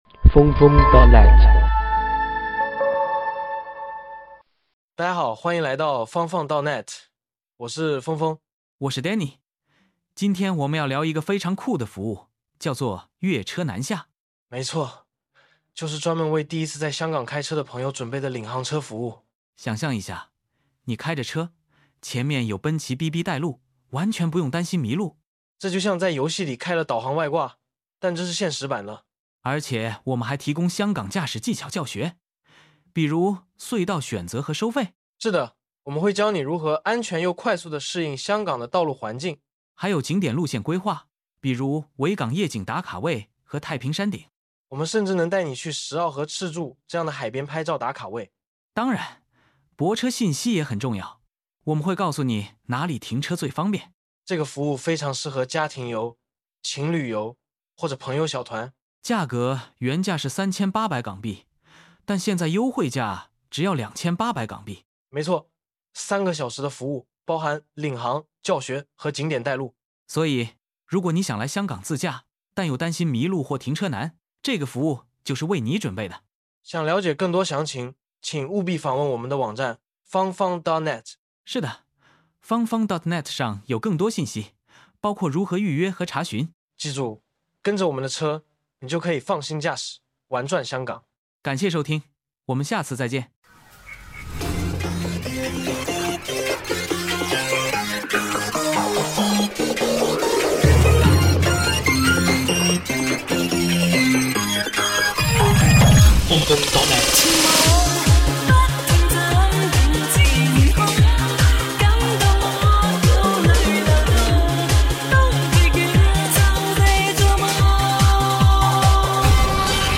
🔼 用普通話收聽這頁內容